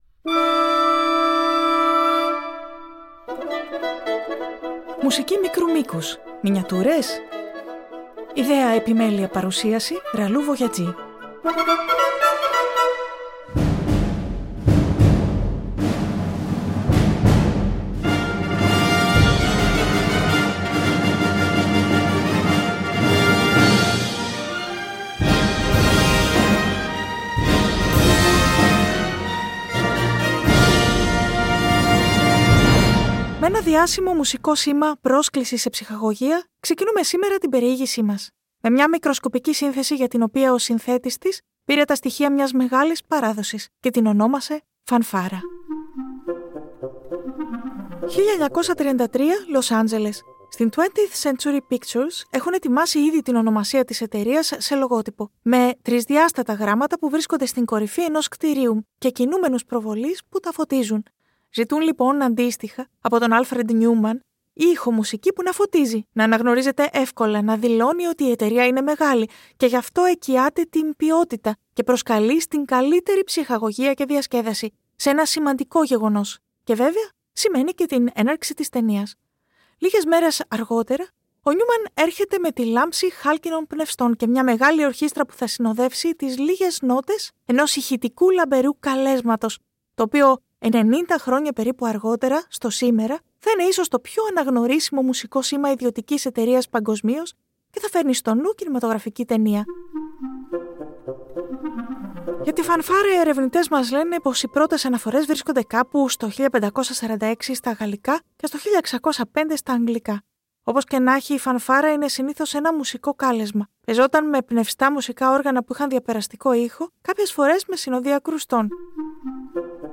Με Φανφάρες!